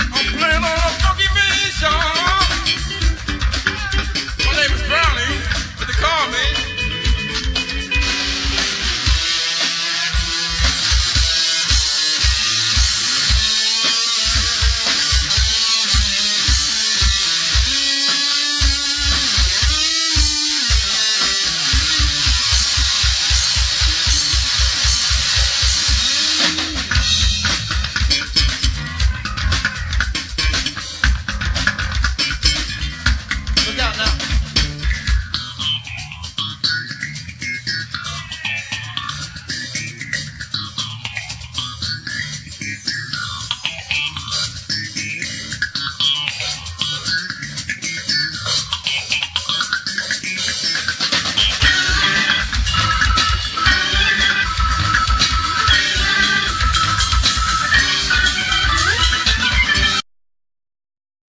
cover tunes